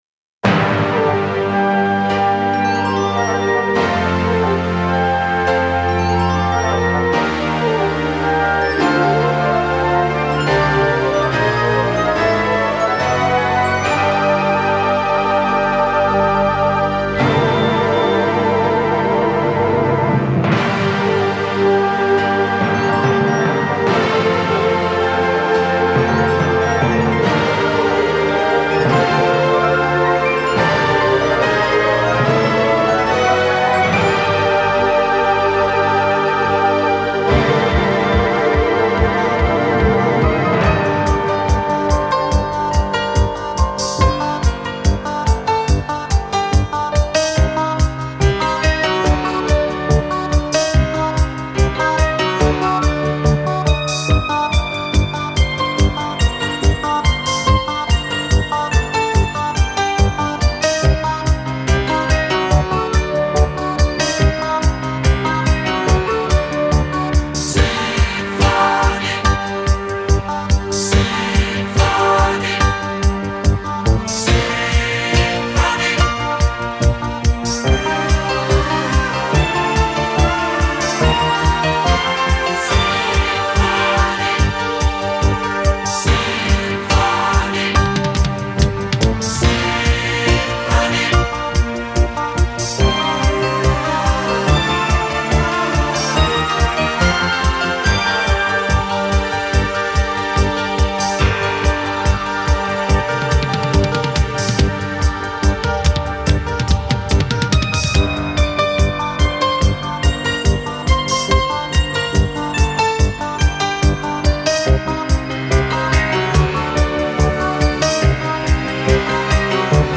Спасибо, но студийный вариант найти - не проблема.